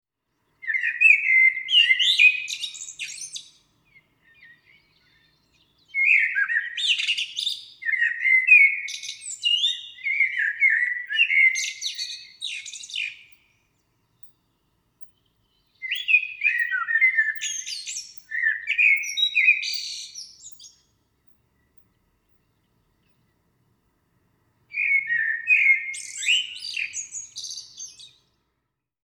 Es el canto del mirlo común, uno de esos vecinos alados que forman parte del paisaje emocional y cultural de la isla.
Su canto, una firma al amanecer
El canto del mirlo es melodioso, variado y profundo (Pulse el enlace para escucharlo). No repite mecánicamente un sonido, sino que (recordando al sinsonte cubano) improvisa, modula, dialoga con el silencio desde puntos elevados del terreno, especialmente al amanecer y al atardecer.
mirlo.mp3